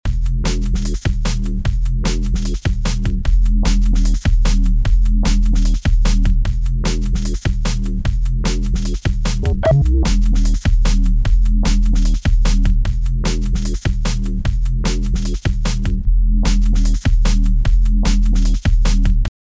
• draw a transpose around the 3rd bar of the clip
4. now do the same with the drum loop in scene three and manipulate the clip gain.